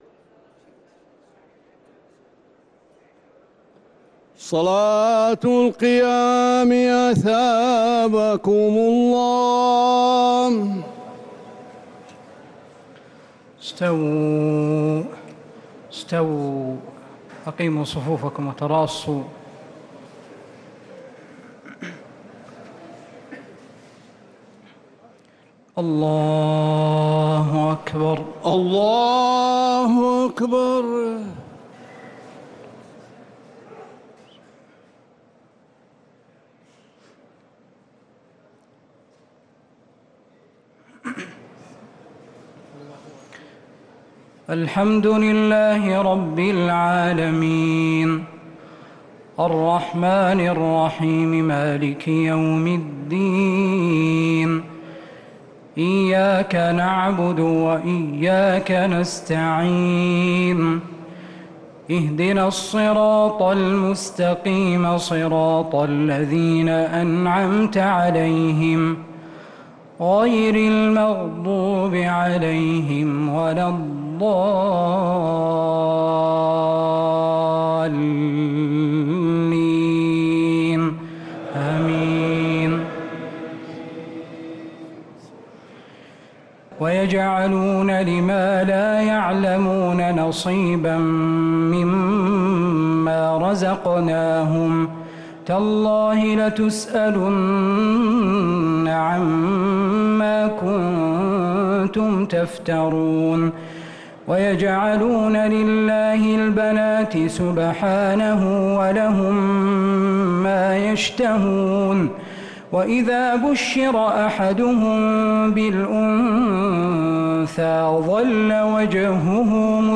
صلاة التراويح ليلة 19 رمضان 1445
الثلاث التسليمات الأولى صلاة التراويح